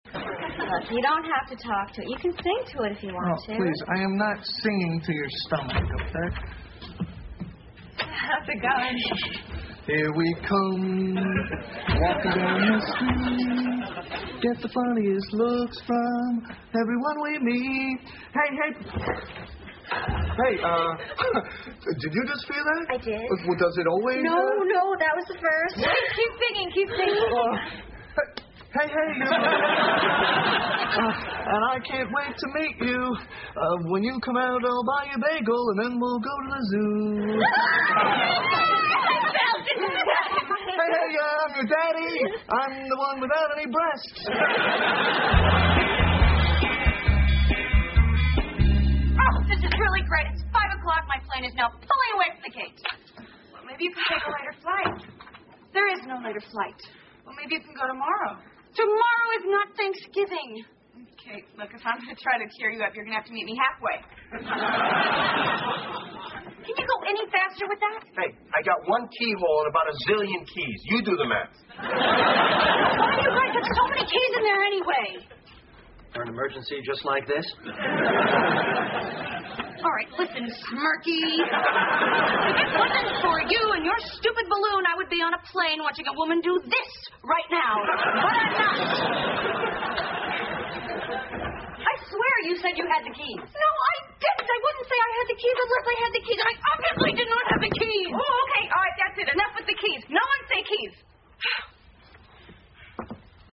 在线英语听力室老友记精校版第1季 第109期:气球飞走了(10)的听力文件下载, 《老友记精校版》是美国乃至全世界最受欢迎的情景喜剧，一共拍摄了10季，以其幽默的对白和与现实生活的贴近吸引了无数的观众，精校版栏目搭配高音质音频与同步双语字幕，是练习提升英语听力水平，积累英语知识的好帮手。